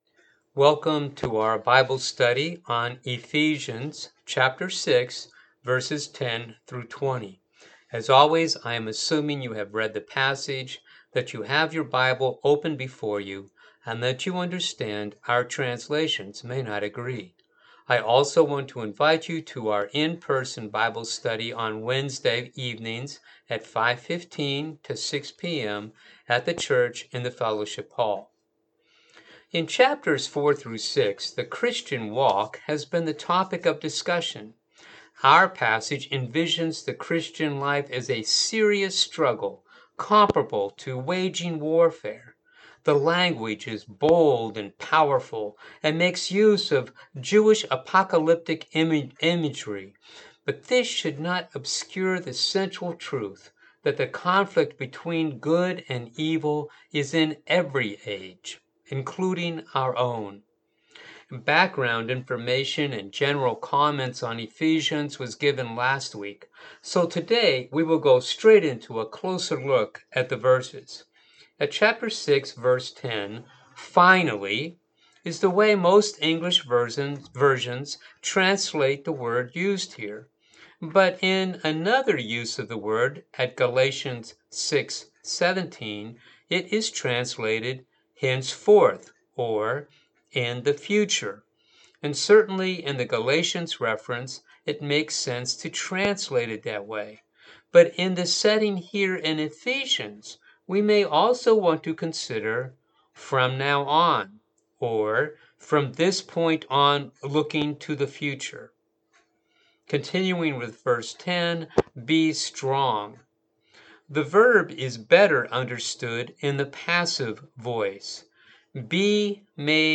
Bible Study for the August 22 Service